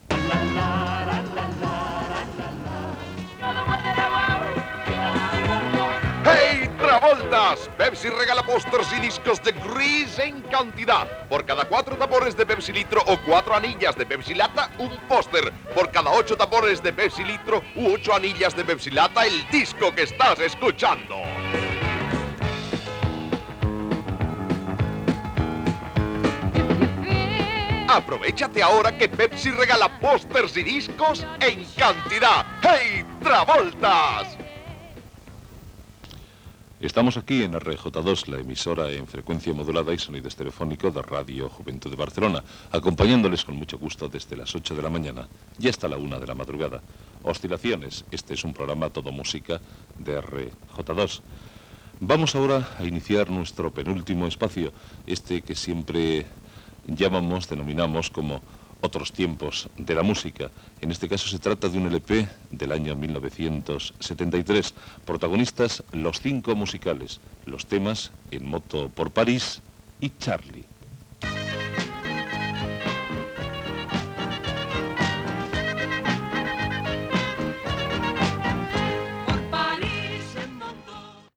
Publicitat, identificació i tema musical.
Musical
FM